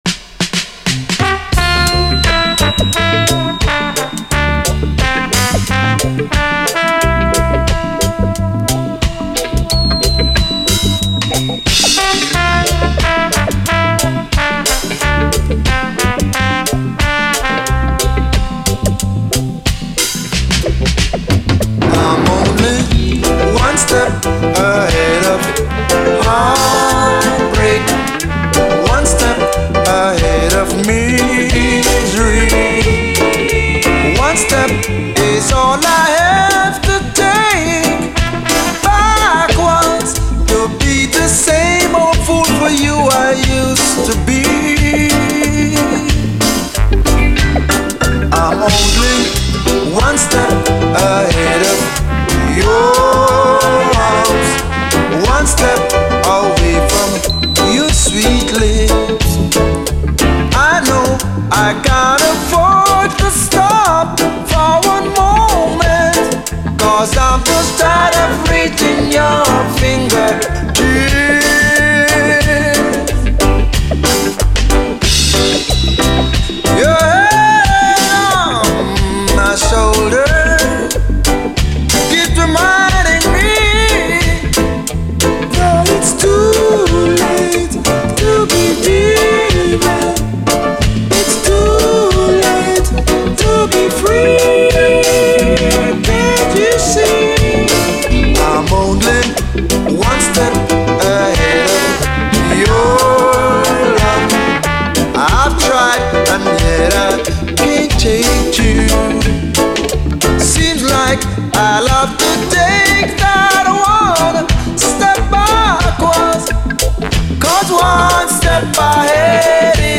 REGGAE
試聴ファイルはこの盤からの録音です
エレガントなピアノ＆トロンボーンにピュンピュン・アレンジも加わった極上プロダクションが超最高。
終盤でダブに接続。